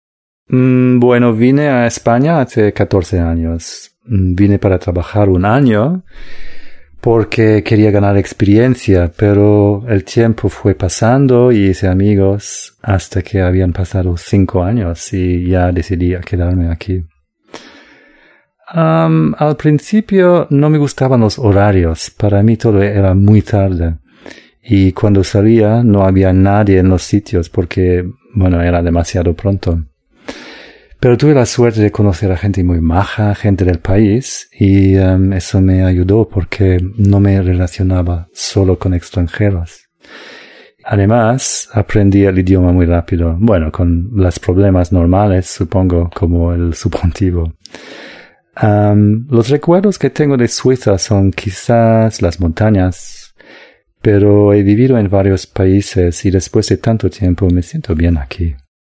Va a escuchar a cuatro personas que hablan de su experiencia viviendo en el extranjero.